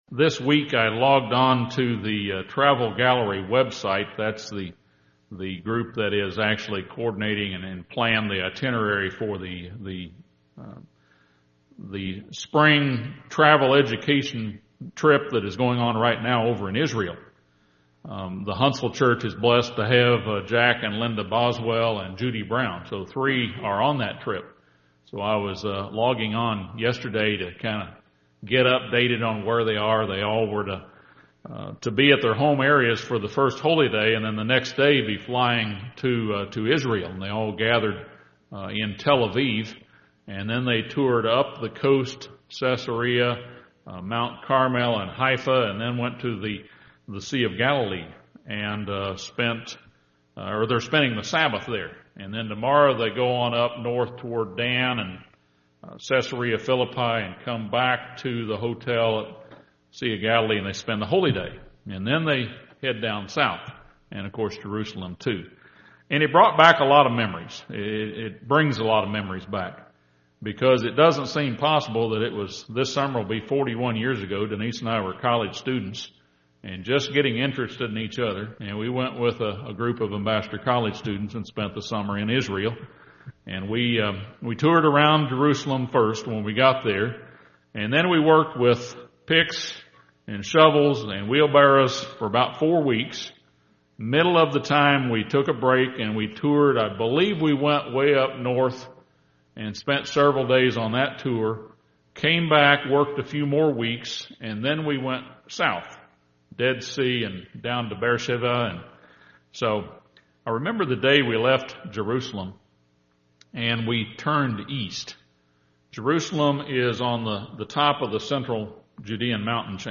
This sermon expounds six lessons we can glean from the events near Jericho.